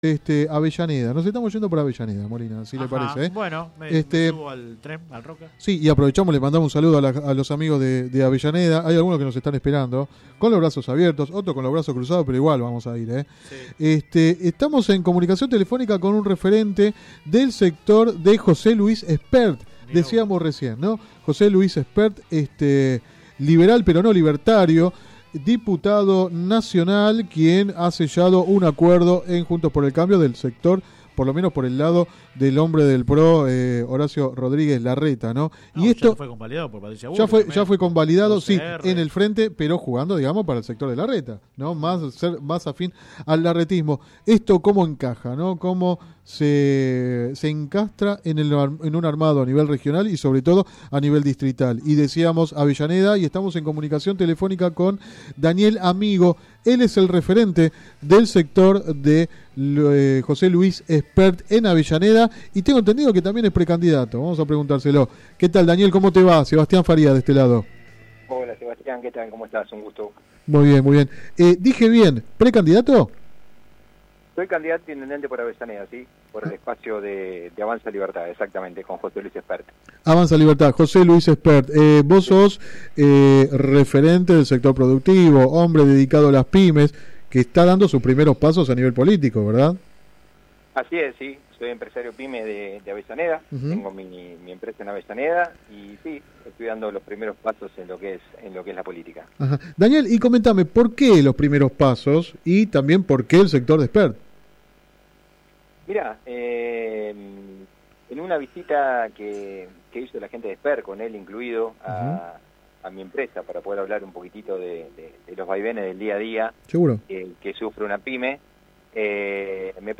Linck acá entrevista radial https